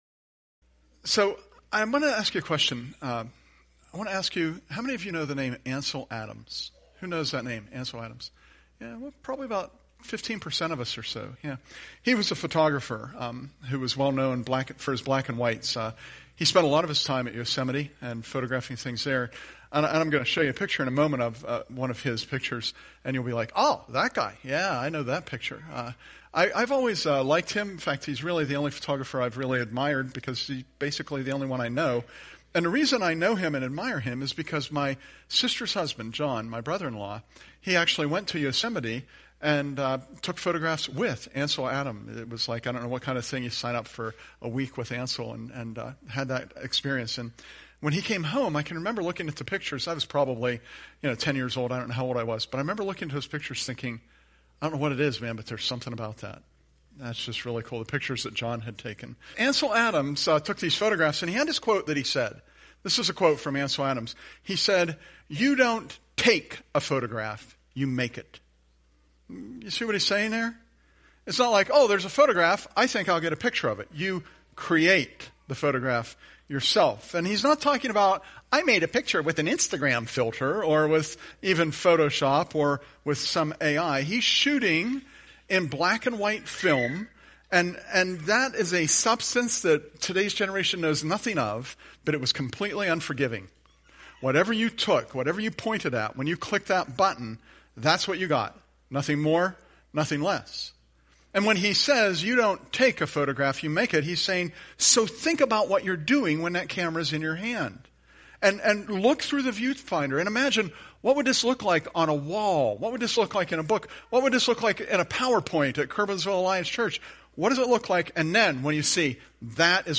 Presented at Curwensville Alliance on 12/22/24